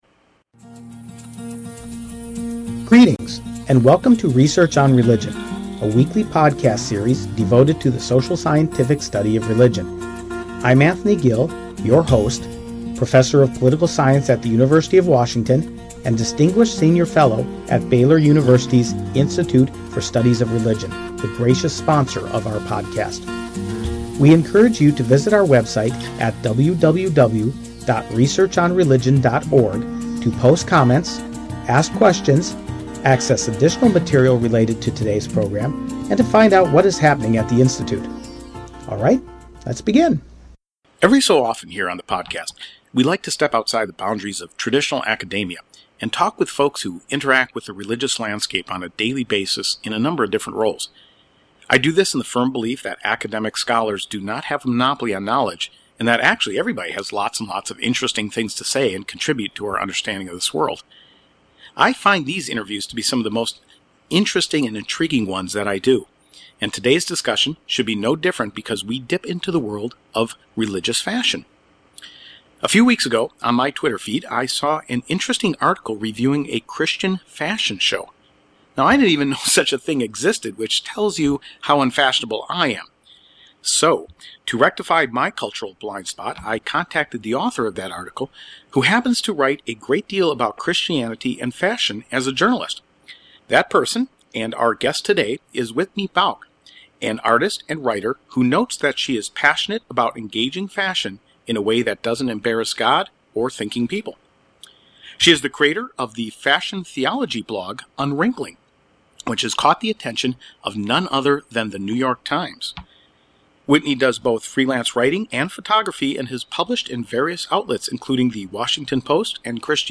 This is a fascinating conversation into something few people actually know about.